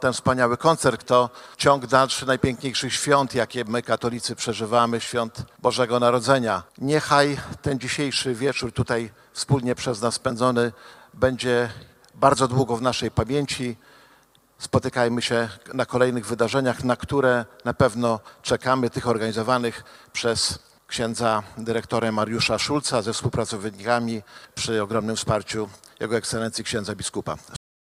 Starosta łomżyński Lech Szabłowski podkreślił, że dla Powiatu Łomżyńskiego bycie partnerem tego typu wydarzeń to „ogromna radość”.